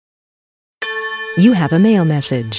you have mail msg 特效人声下载